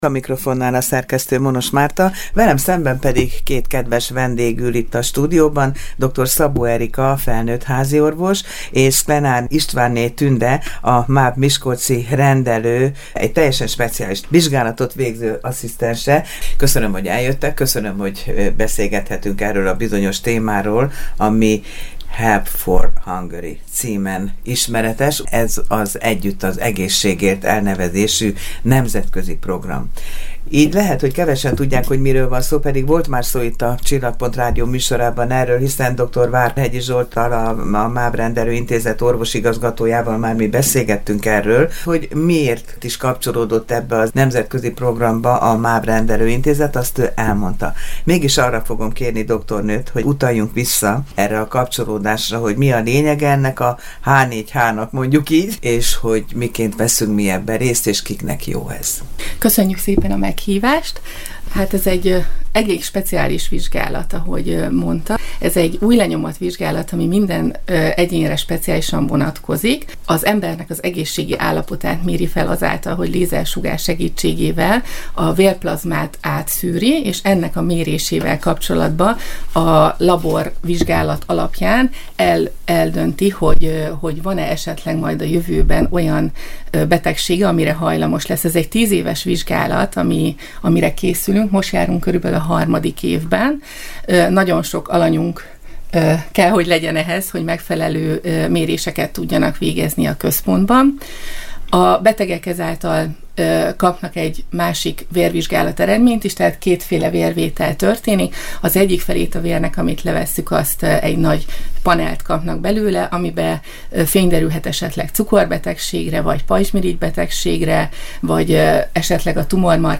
Ennek részleteiről tájékoztatta a hallgatókat a Csillagpont Rádió két vendége